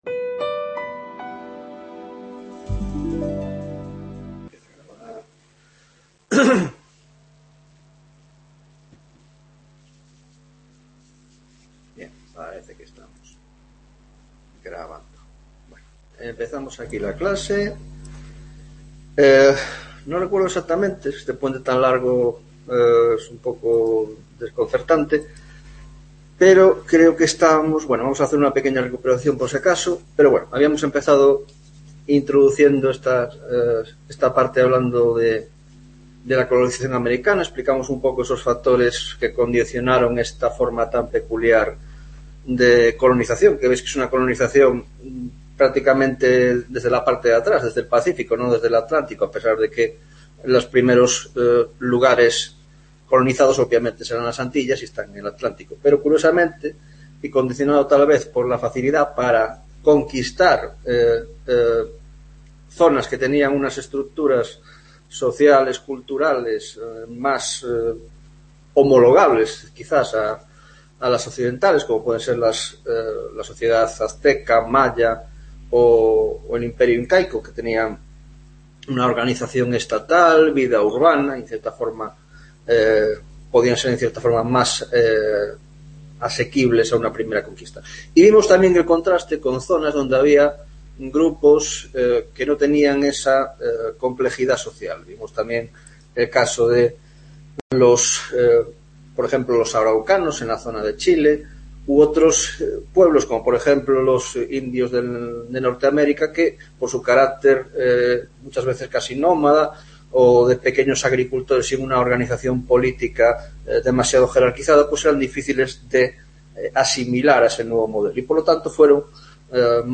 7ª Tutoria de Historia Moderna